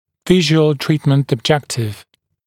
[‘vɪʒuəl ‘triːtmənt əb’ʤektɪv][‘вижуэл ‘три:тмэнт эб’джектив]анализ VTO, визуализированная цель лечения, визуализированный результат лечения